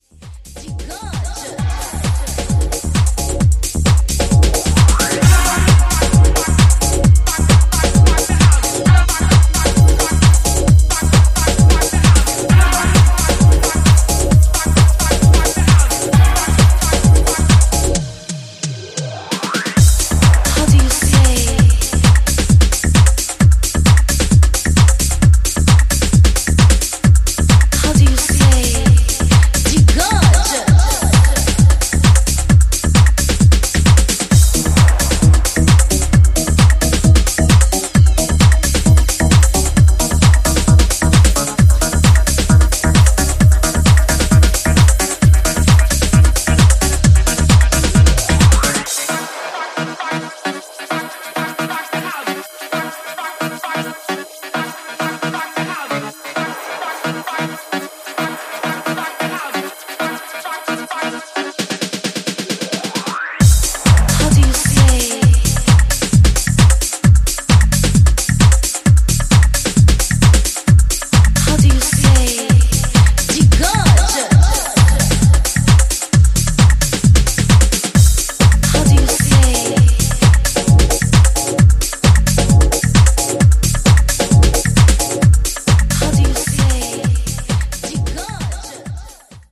トランシーなリフから爽快感溢れる